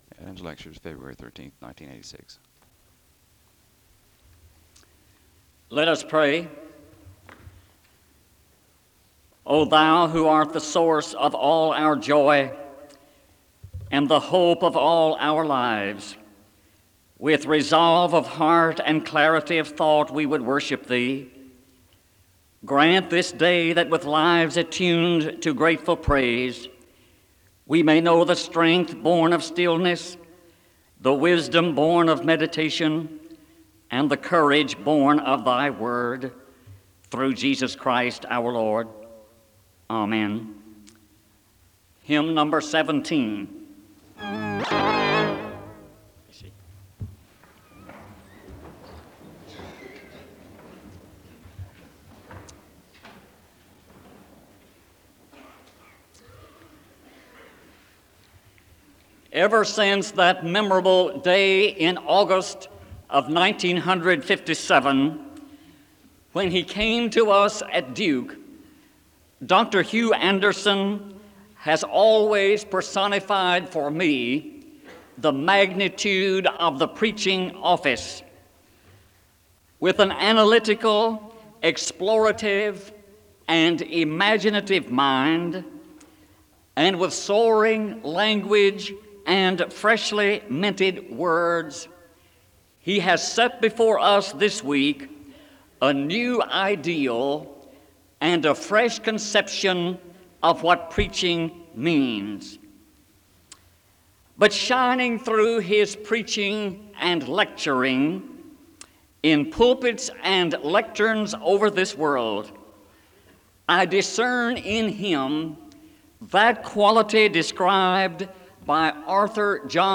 The service begins with a word of prayer (0:00-0:45).
There is an announcement for a picture taking session (54:47-55:14). The service closes in a blessing (55:15-55:34).
SEBTS Chapel and Special Event Recordings SEBTS Chapel and Special Event Recordings